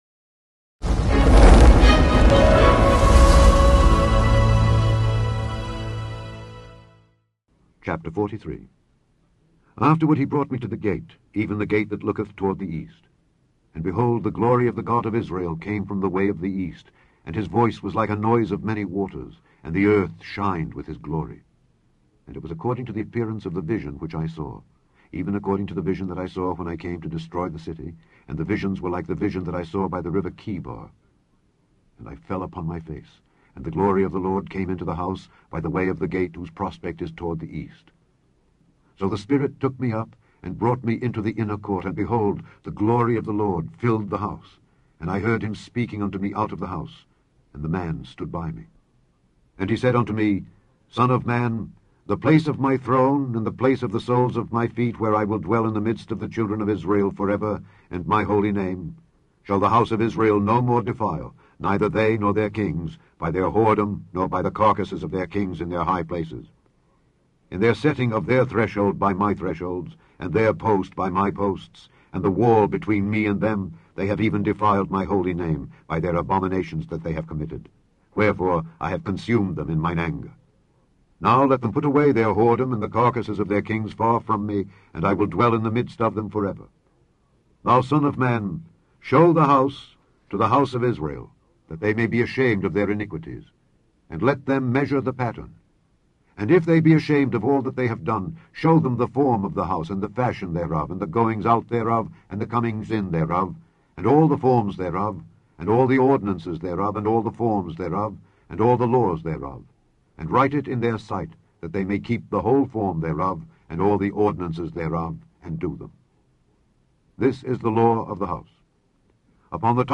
Daily Bible Reading: Ezekiel 43-48
Click on the podcast to hear Alexander Scourby read Ezekiel 43-48.